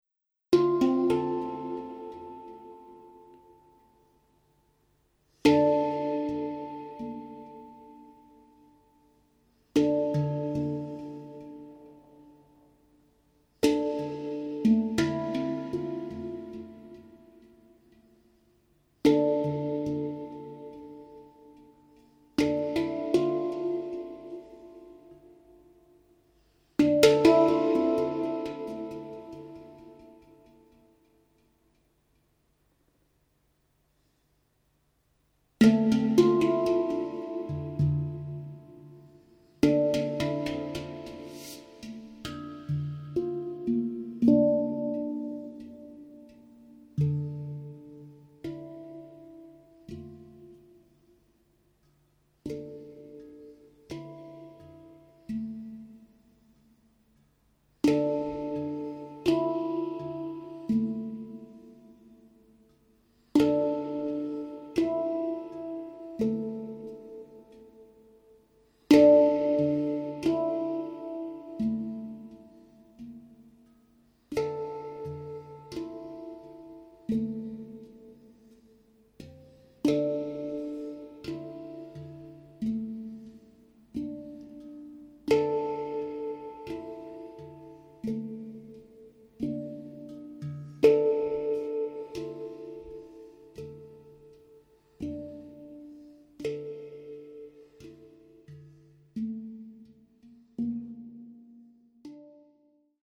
Music for Meditation & Yoga
Since 2009 he played the HANG